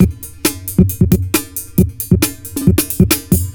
ELECTRO 14-R.wav